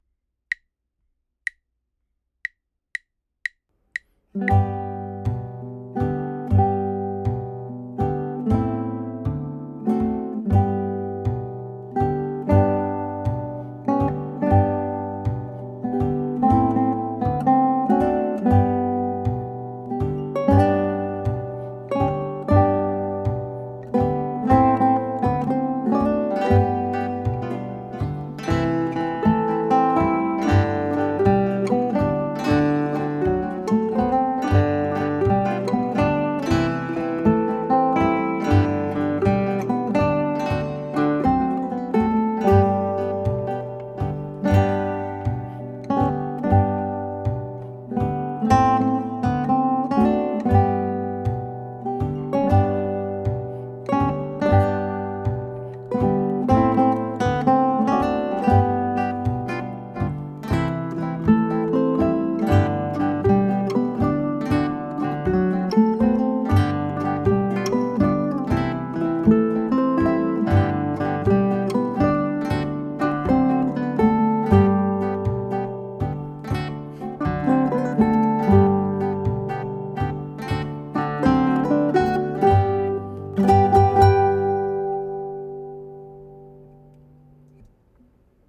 Next, we take a day trip to the Caribbean and learn a syncopated and upbeat song, Yellow Bird.
Yellow Bird is a trio, a piece with three separate guitar parts: guitar 1 (melody), guitar 2 (chords) and guitar 3 (bass).
Yellow Bird Rests | Guitar 1 is silent for 4 measures while guitars 2 and 3 play the vamp.
Yellow Bird should be performed at an allegro tempo (120+ BPM).
Yellow_Bird_Trio_GTR_edited.mp3